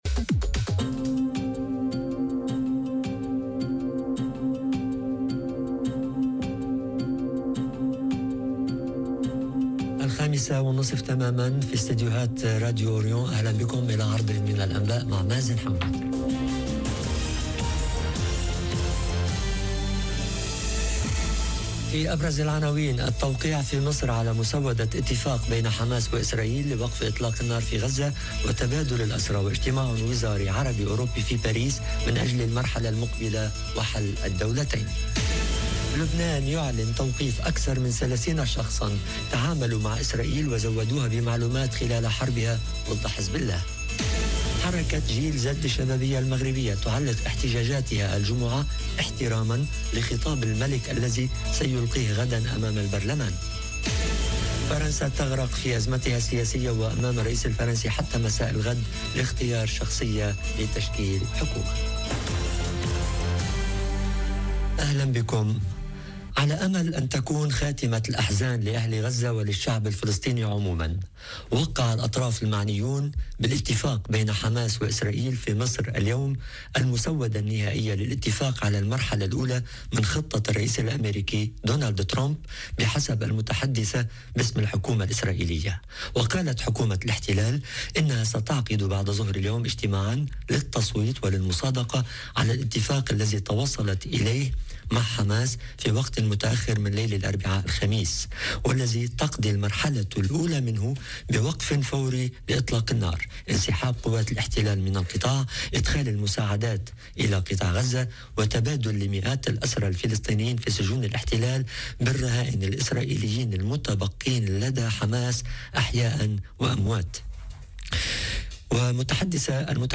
نشرة أخبار المساء: -التوقيع في مصر على مسودة اتفاق بين حماس وإسرائيل لوقف إطلاق النار في غزة - Radio ORIENT، إذاعة الشرق من باريس